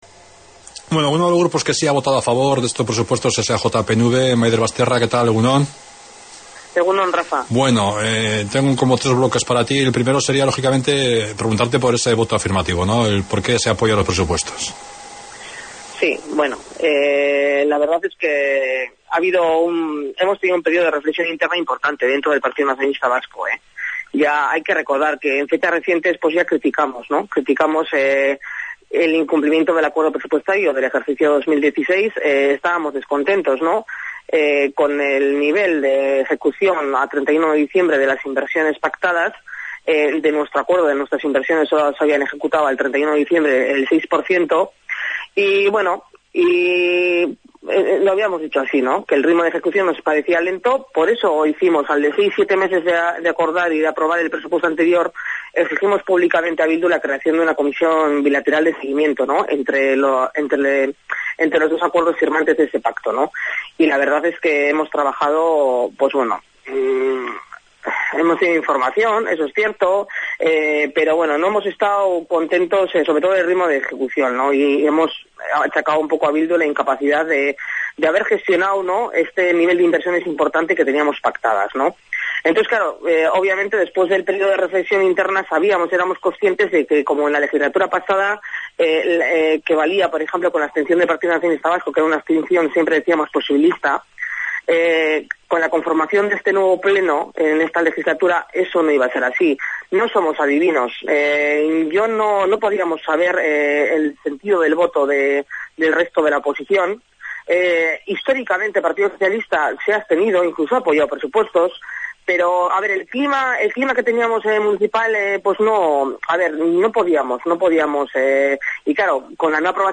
Entrevista de Radio Llodio a nuestra concejala Maider Basterra sobre el acuerdo presupuestario para el 2017